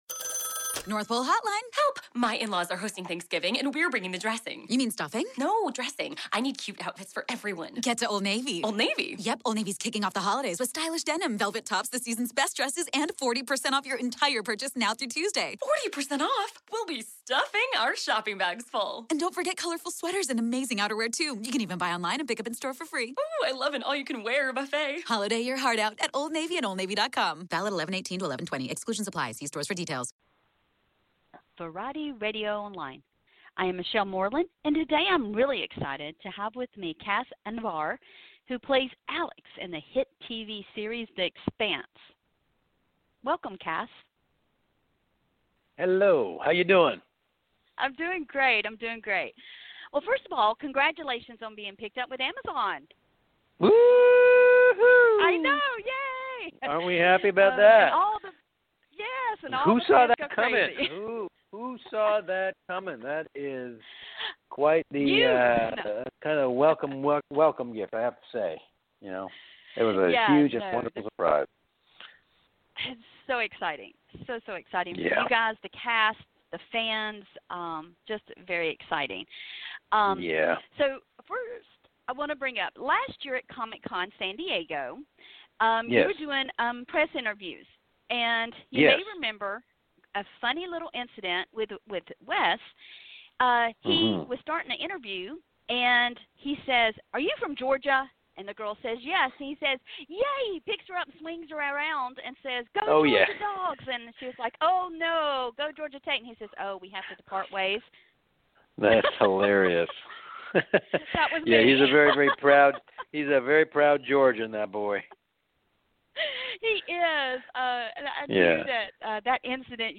Cas Anvar 'The Expanse' Interview